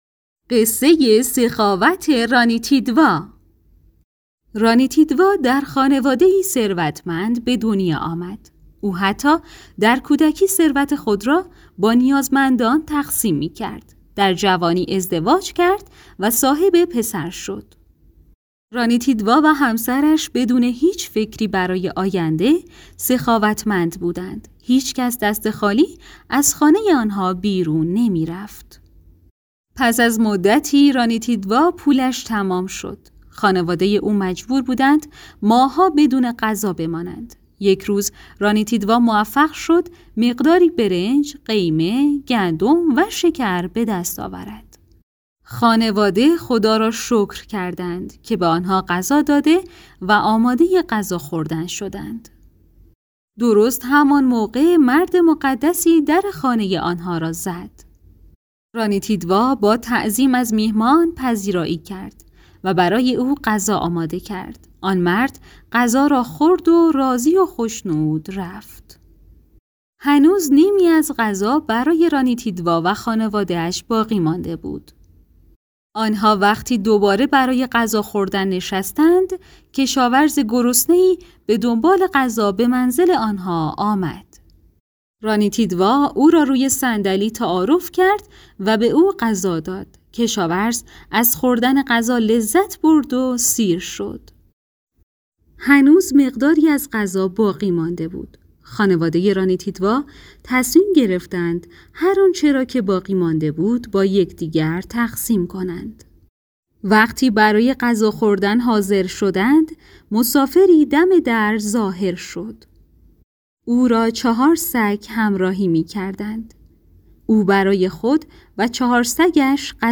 گوینده
قصه های کودکانه